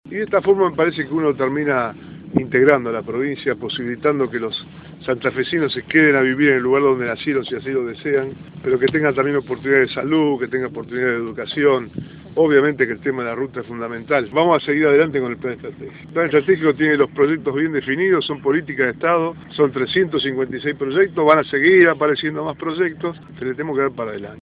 Bonfatti: Declaraciones sobre la obra.